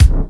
Kick 19.wav